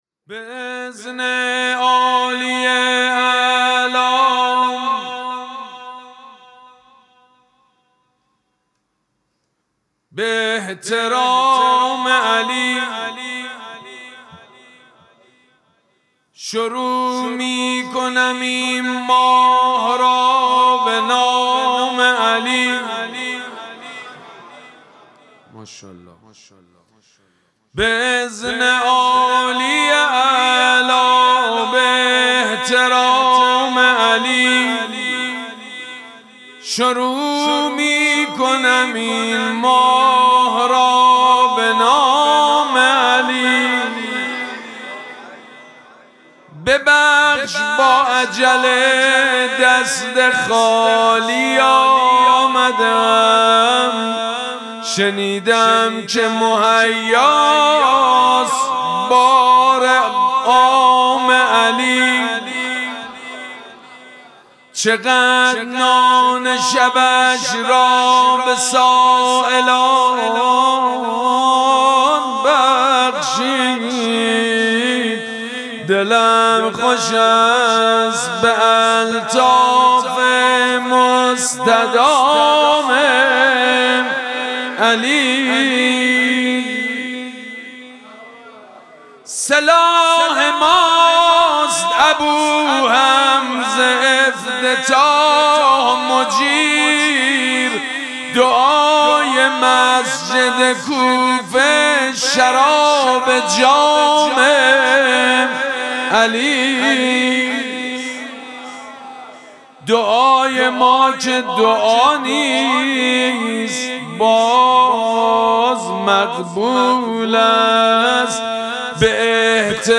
مراسم مناجات شب اول ماه مبارک رمضان
حسینیه ریحانه الحسین سلام الله علیها
مدح